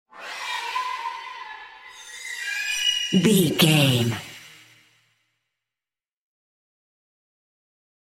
Groaning Devil Stinger High.
In-crescendo
Aeolian/Minor
scary
ominous
dark
eerie
strings
synth
ambience
pads